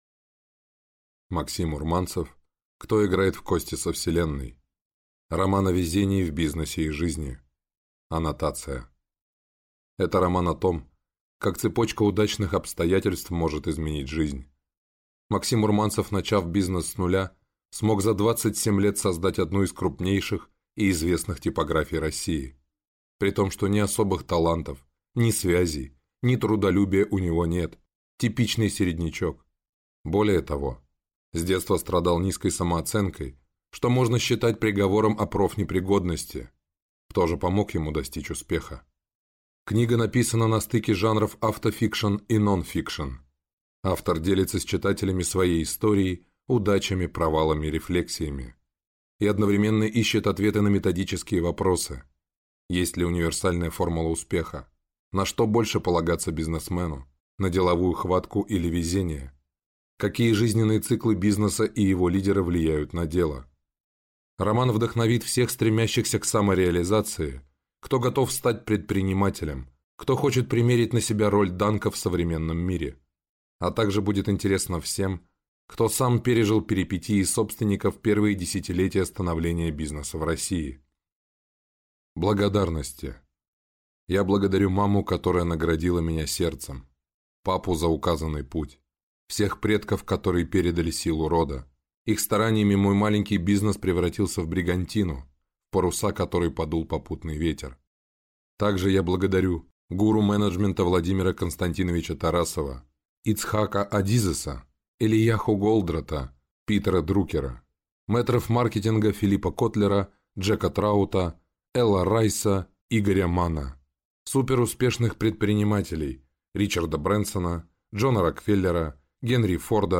Аудиокнига Кто играет в кости со Вселенной?